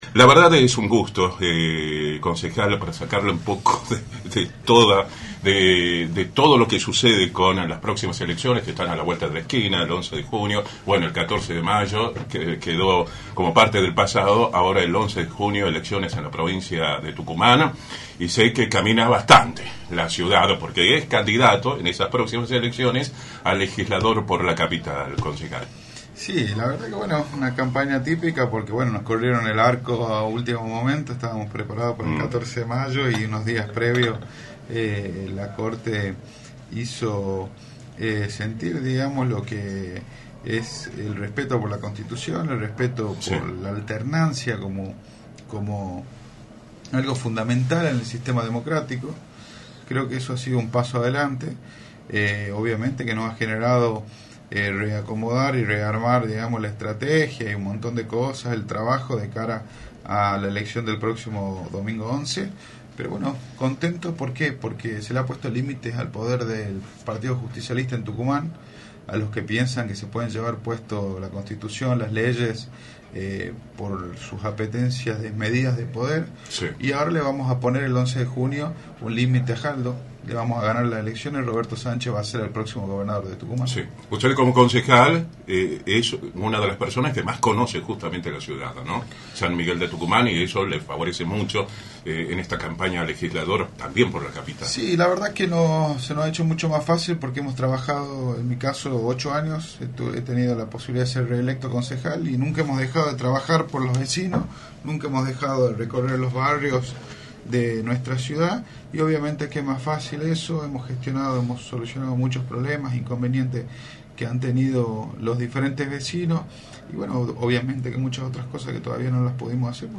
Agustín Romano Norri, Concejal de San Miguel de Tucumán y candidato a Legislador por Juntos por el Cambio, analizó en Radio del Plata Tucumán, por la 93.9, el escenario electoral de la provincia, luego de la suspensión de los comicios y la posterior convocatoria para el próximo 11 de junio.